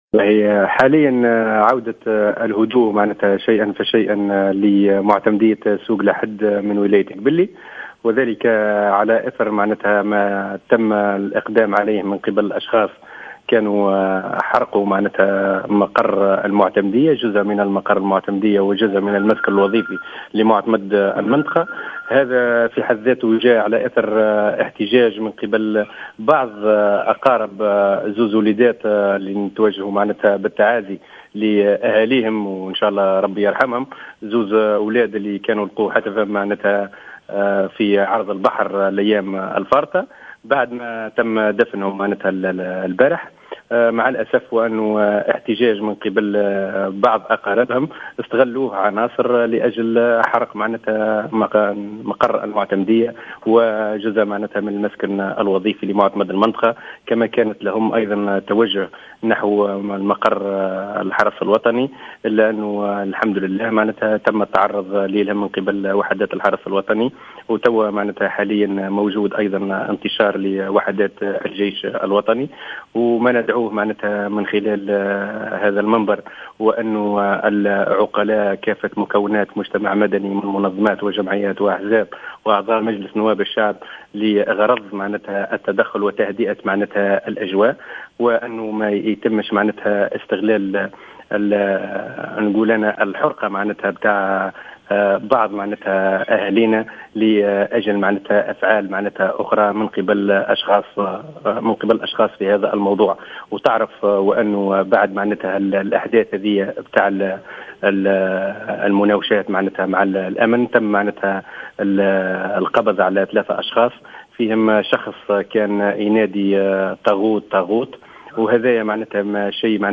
أكد والي قبلي سامي الغابي في تصريح للجوهرة "اف ام" أن الأجواء تتجه للهدوء تدريجيا في معتمدية سوق الأحد وذلك على اثر اقدام بعض الأشخاص على حرق جزء من المعتمدية ومنزل المعتمد على خلفية غرق شابين اصيلي المنطقة في مركب الحراقة الذي اصطدم بخافرة للجيش.